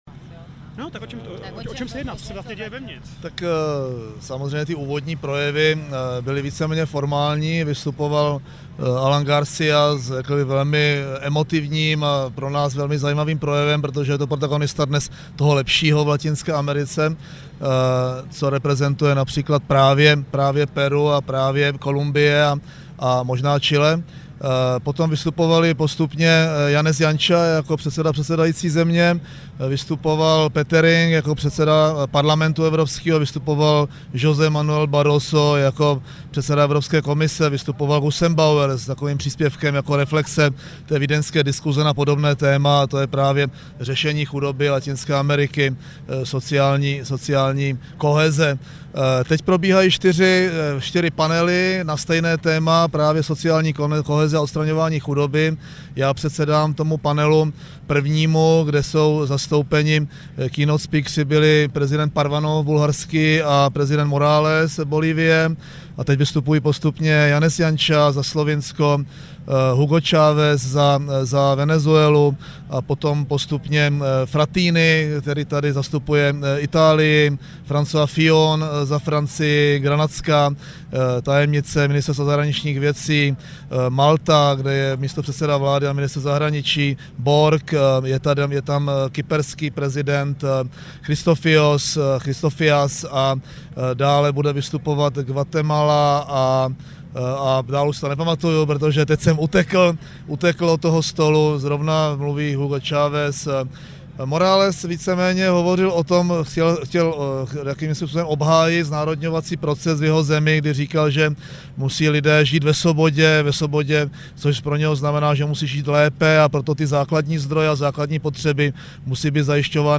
Komentář premiéra M. Topolánka k průběhu summitu EU-LAC v peruánské Limě